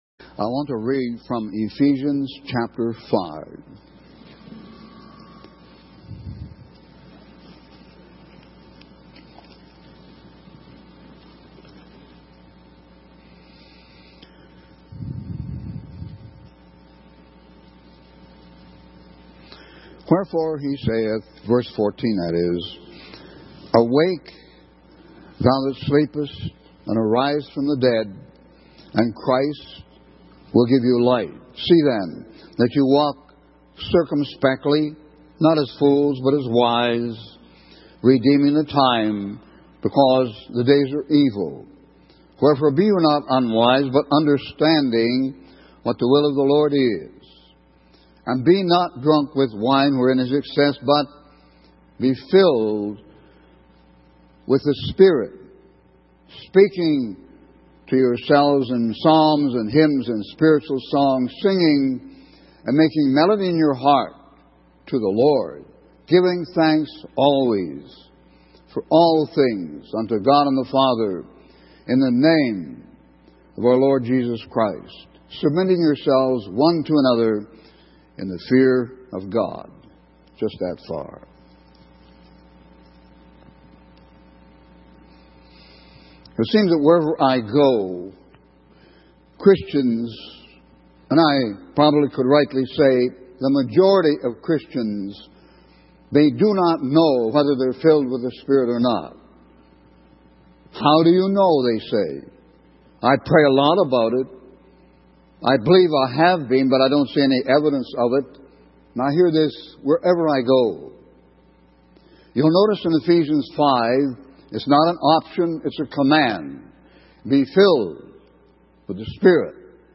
In this sermon, the preacher emphasizes the importance of submitting oneself to God's will. He shares a personal experience of initially resisting God's call to preach, but eventually surrendering and experiencing the power of God. The preacher encourages listeners to dethrone the idol of self-sufficiency and allow Christ to sit on the throne of their lives.